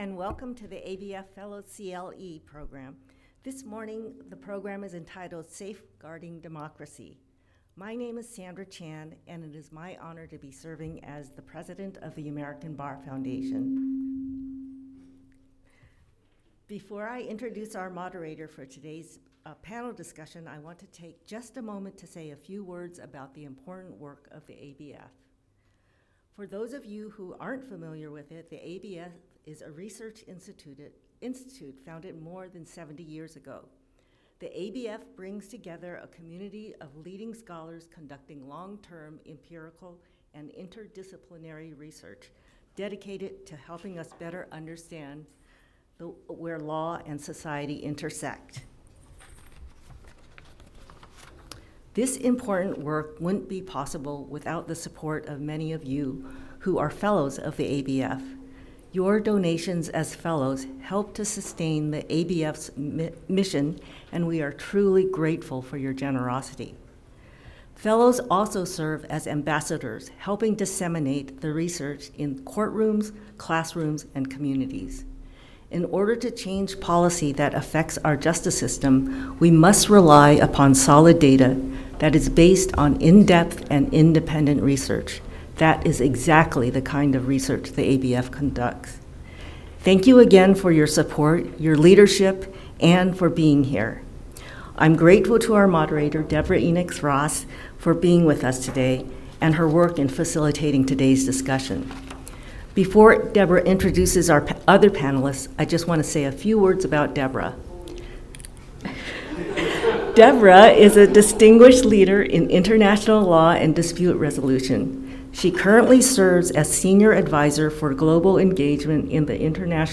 The Fellows events kicked off with the Fellows CLE Friday morning, featuring a panel moderated by past ABA President Deborah Enix-Ross and a discussion with ABF…